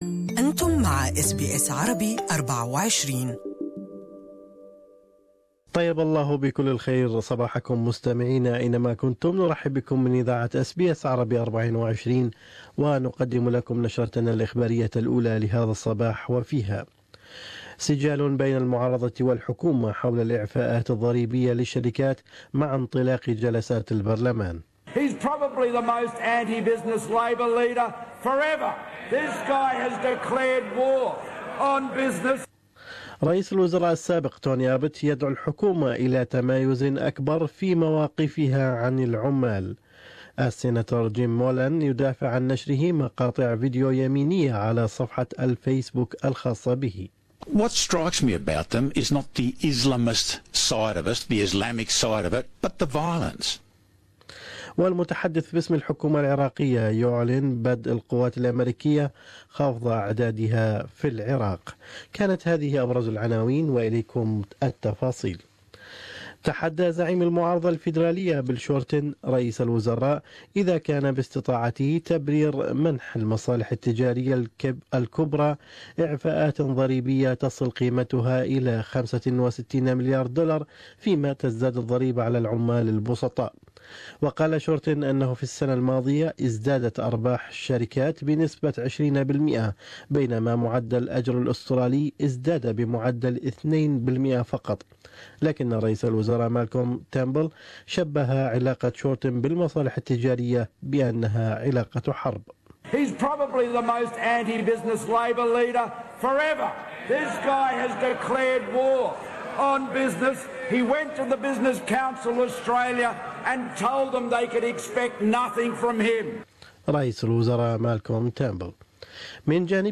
News Bulletin: A new Liberal senator unapologetic after sharing anti-Muslim clips on Facebook